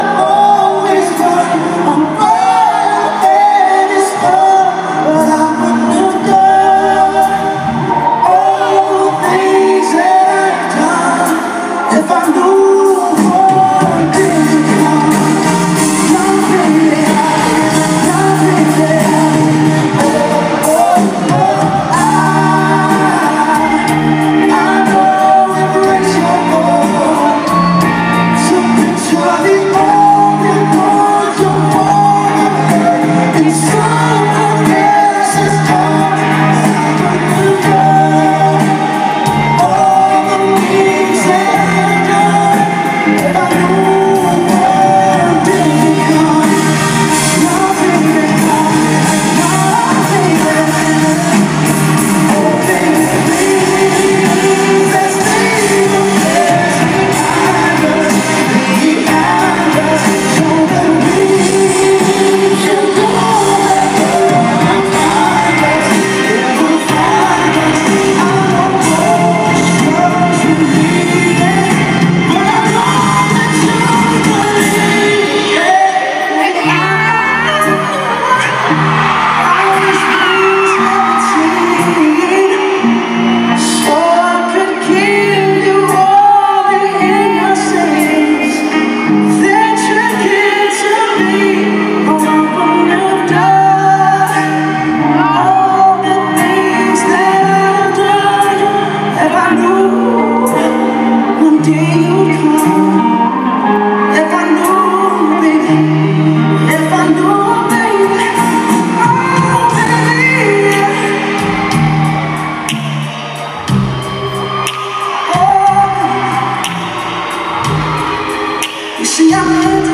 His voice is mesmerizing as was the show.